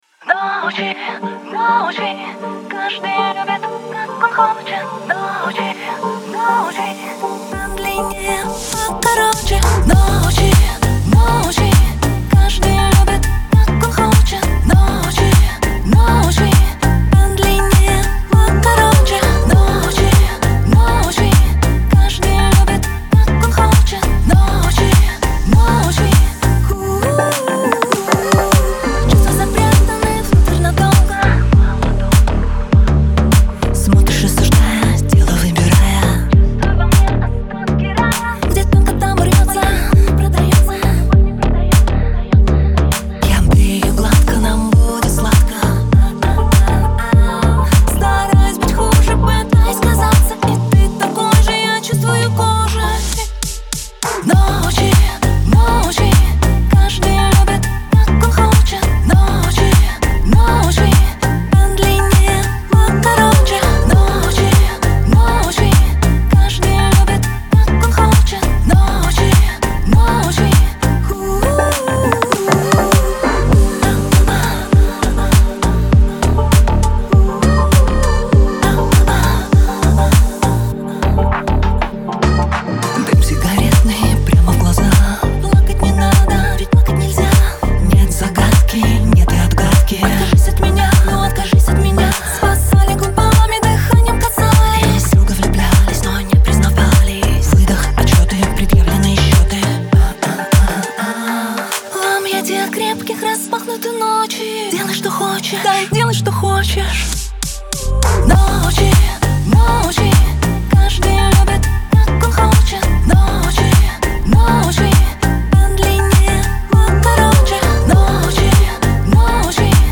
pop , эстрада
дуэт